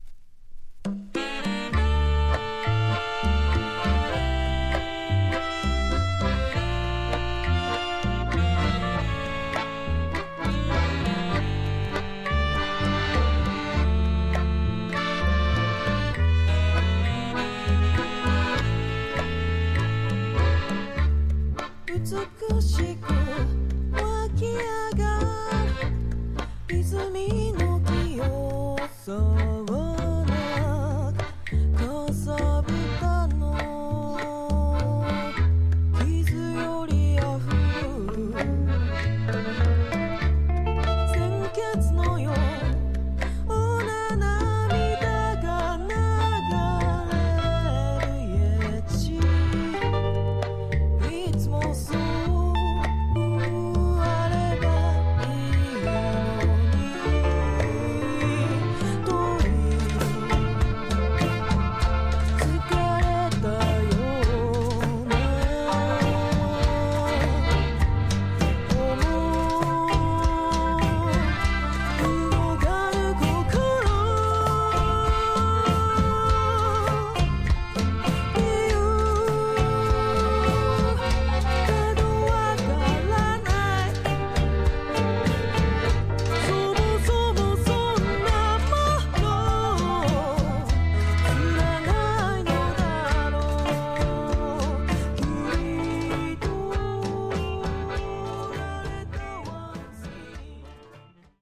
Tags: Japan , Caribbean
Haitian / French-Carib inspired ensemble